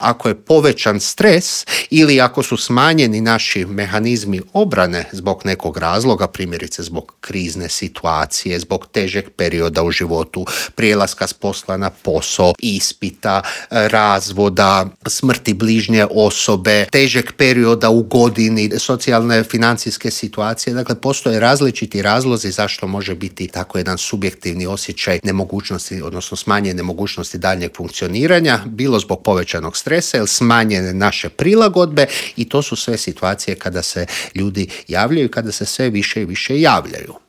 ZAGREB - Najdepresivniji je dan u godini pa smo u studiju Media servisa razgovarali o mentalnom zdravlju.